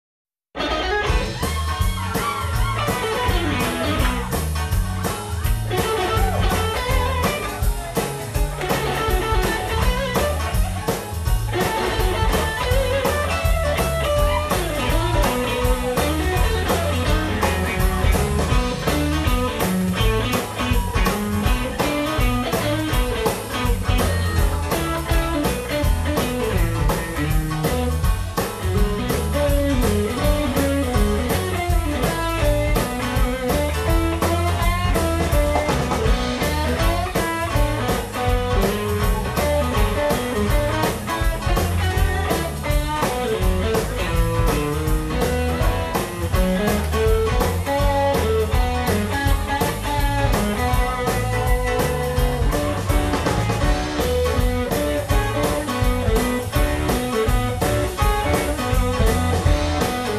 Rhythm & Blues